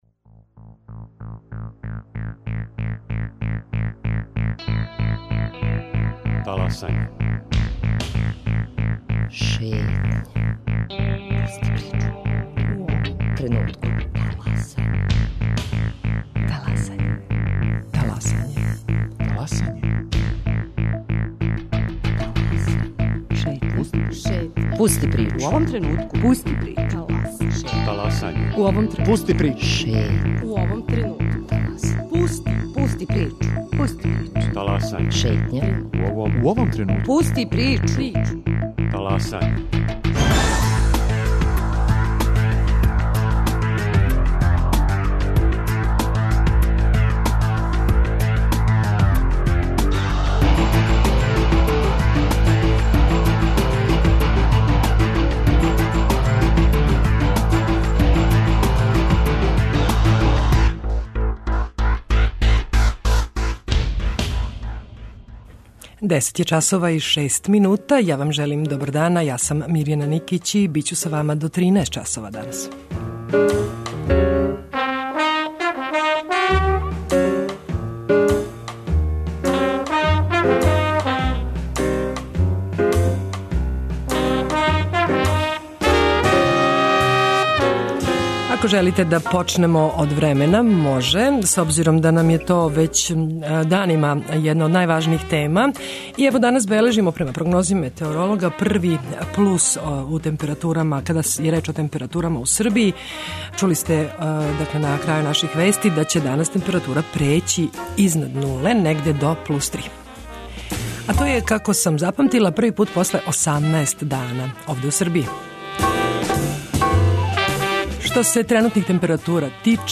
Гост Шетње је историчар Чедомир Антић.